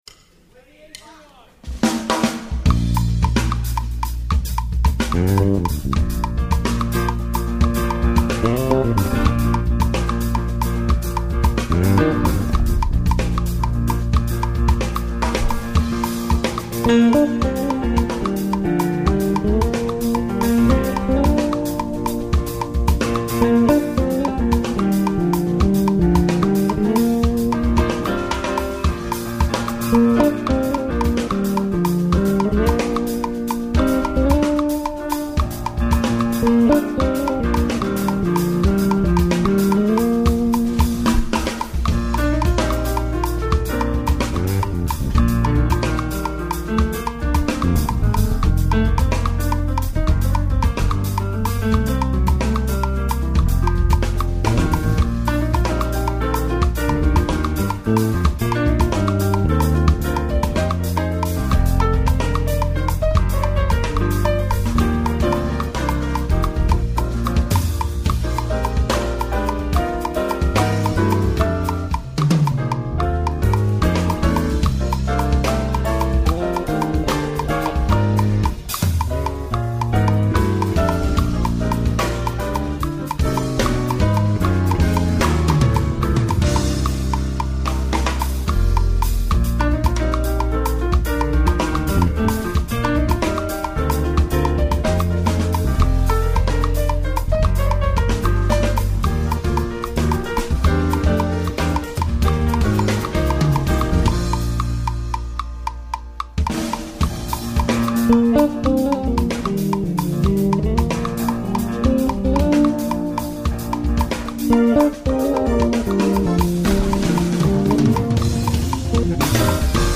Ist in 6/8.
Die Band spielt 6/8-Feeling von vorne bis hinten.